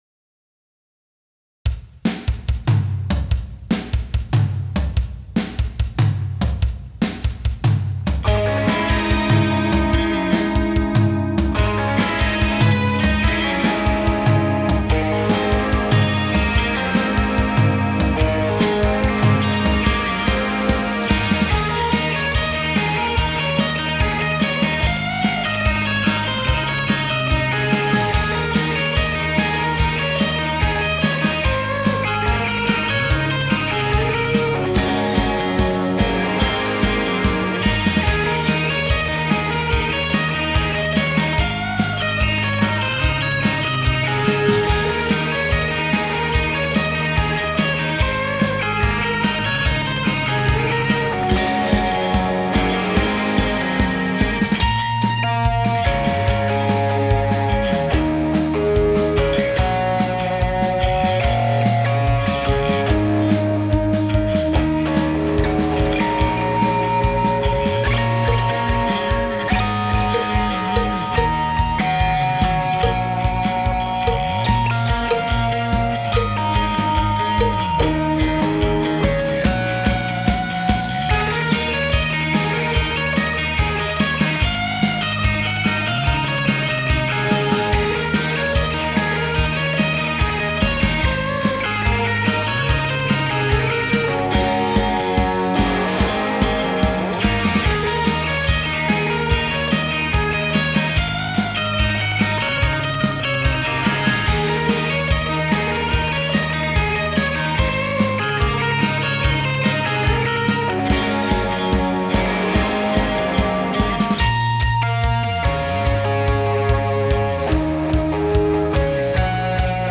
BPM : 145
Tuning : Eb
Without vocals